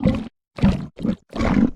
Sfx_creature_spikeytrap_eat_01.ogg